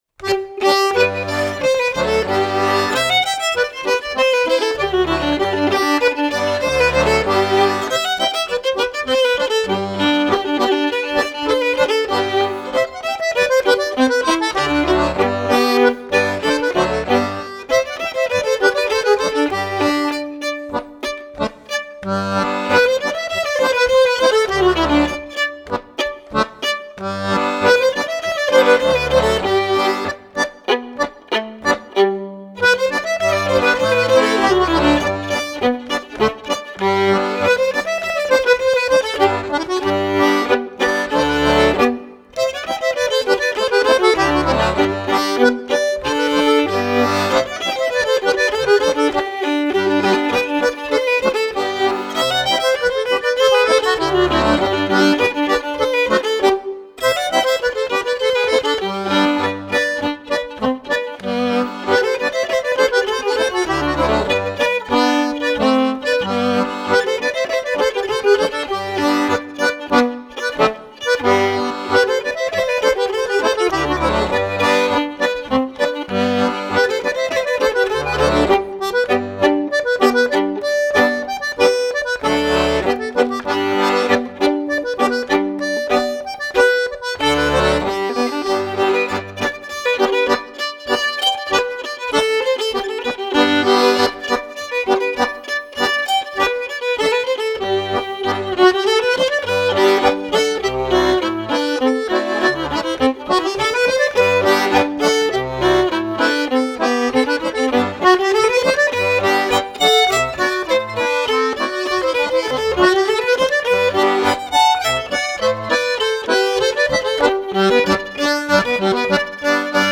violon